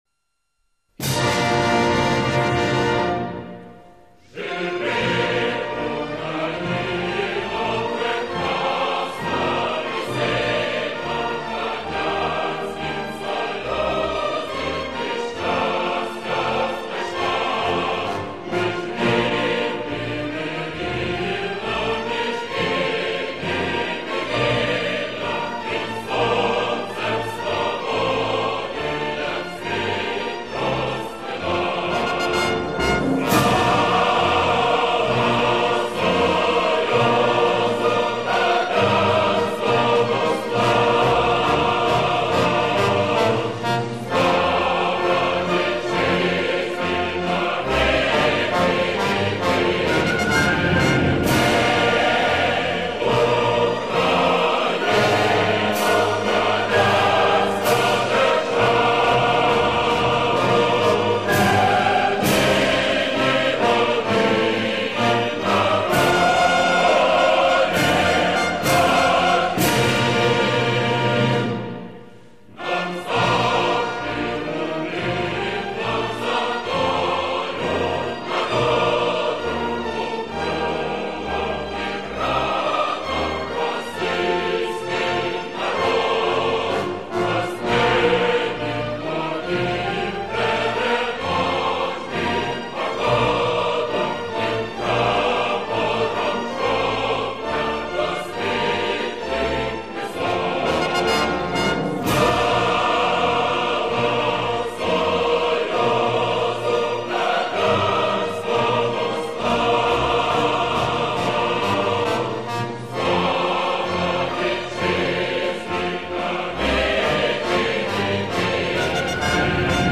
歌詞：（ウクライナ語）